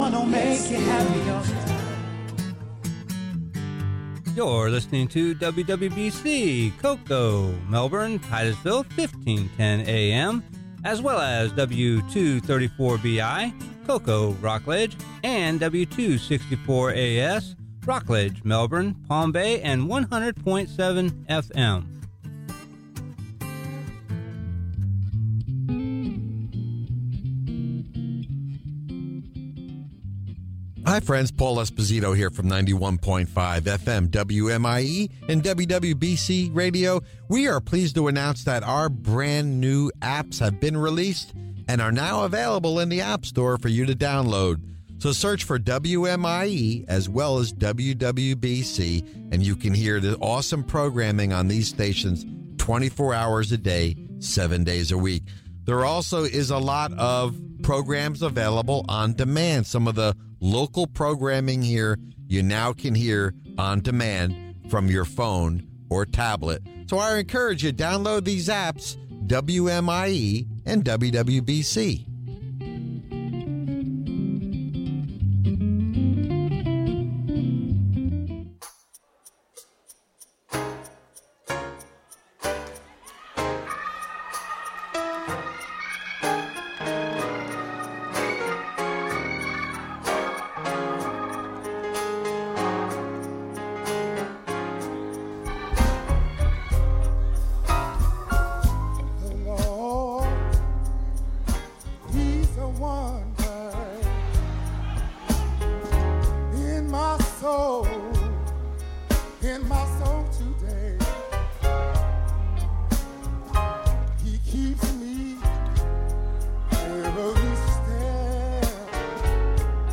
Sermon: Beware of the Dogs, Philippians Ch. 2 Part 2